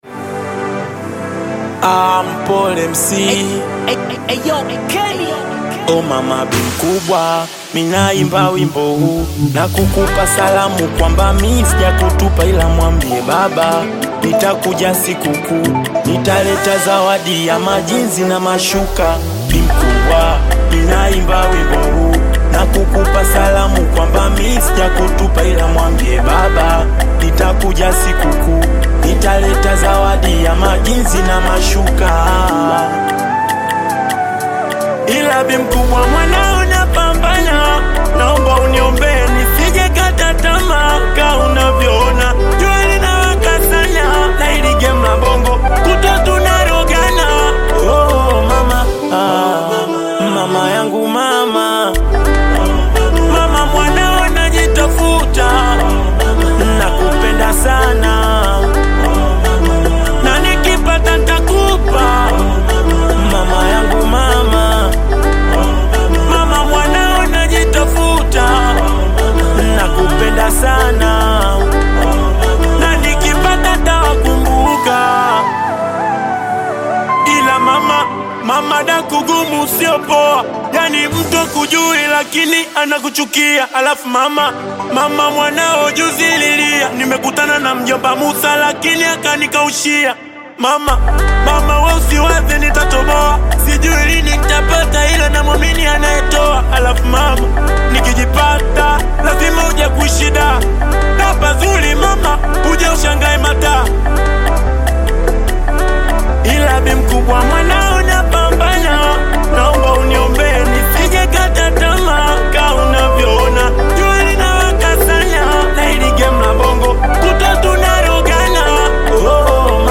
Tanzanian Bongo Flava Singeli producer
Singeli You may also like